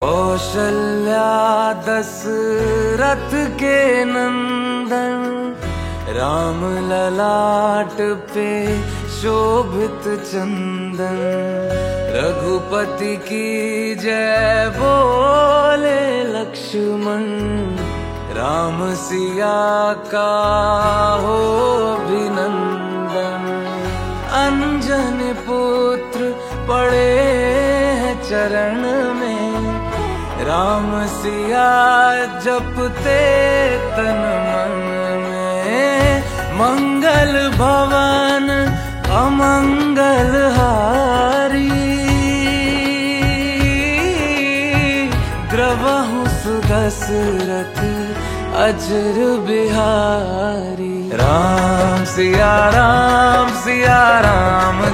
Bhakti Ringtones
Devotional Ringtones